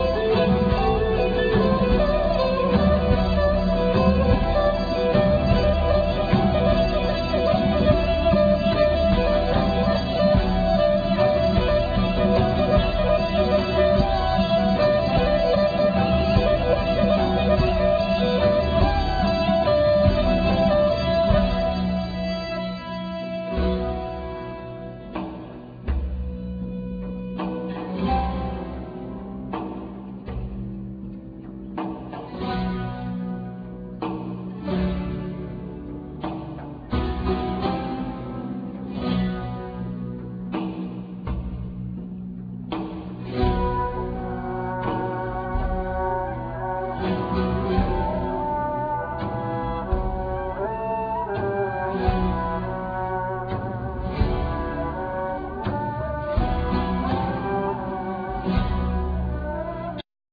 Zarb,Bendir,Udu
Zarb,Daff
Ney
Cello
Lyra,Saz,Rabab,Kemanche,Oud,Laouto